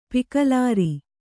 ♪ pikālāri